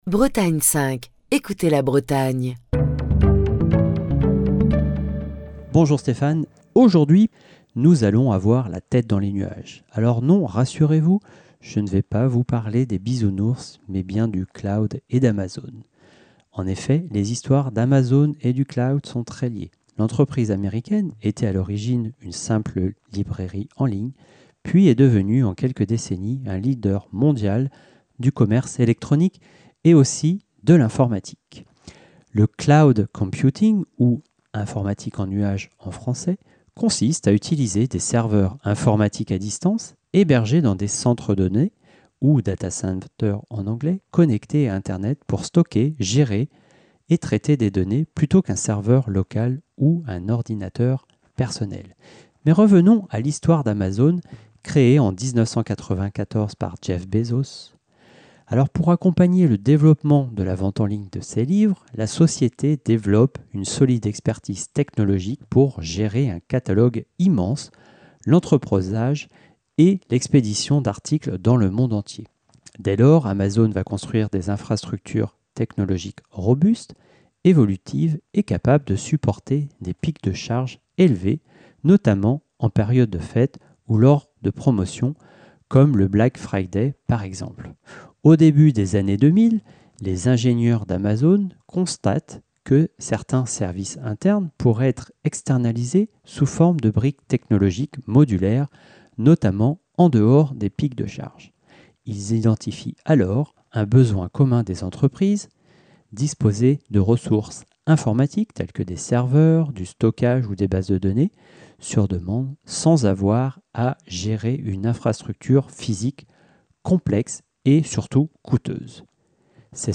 Chronique du 15 janvier 2025.